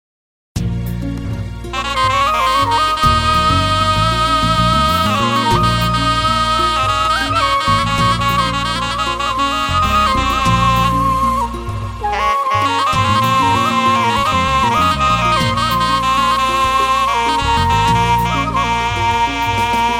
Thể loại nhạc chuông: Nhạc không lời